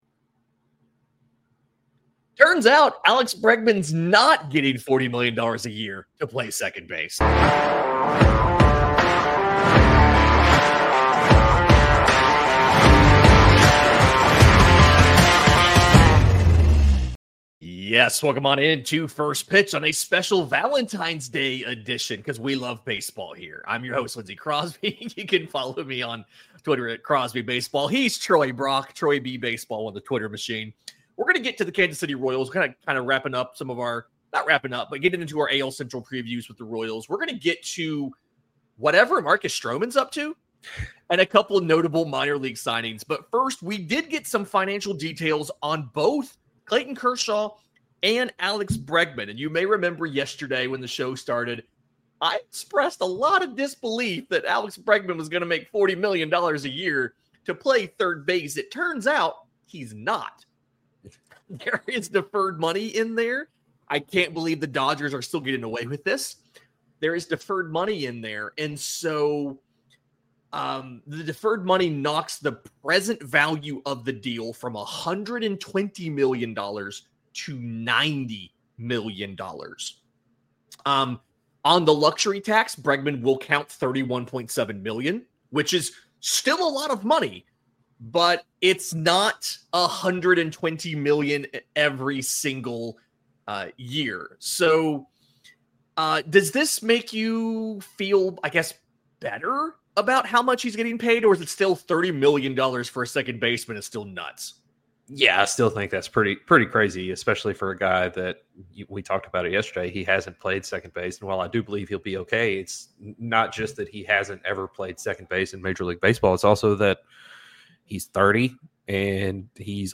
The financial details are out on the deferred contract of Alex Bregman with the Boston Red Sox and incentives from the Los Angeles Dodgers for Clayton Kershaw. Also, the guys talk about Marcus Stroman sitting out of camp (recorded before he reported on Friday morning) and preview their pick to win the AL Central, the Kansas City Royals. Welcome in to First Pitch, a show coming at you every weekday covering what's going on around Major League Baseball.